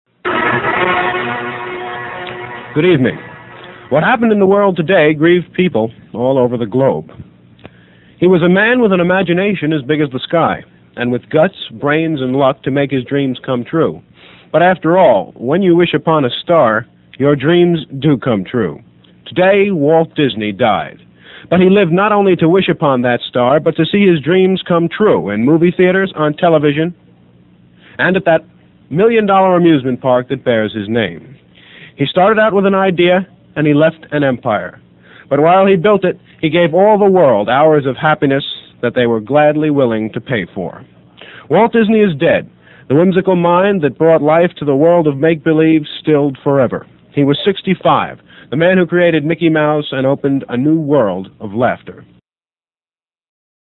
A portion of "The World Today," a daily half-hour newscast on WRTI-FM.